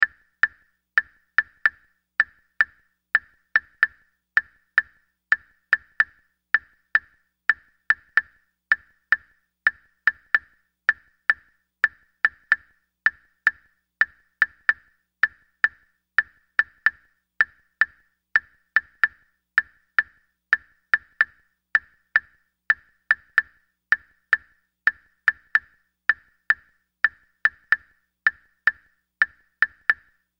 Прямой (записанный на ноты) клаве в 4/4, 112 bpm
written_clave_4-4.mp3